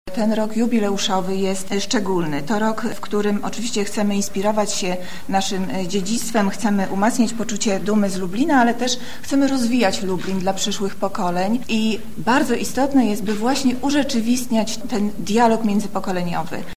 – mówi Monika Lipińska, zastępca prezydenta Lublina do spraw społecznych.